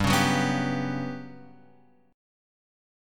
G Major 7th Flat 5th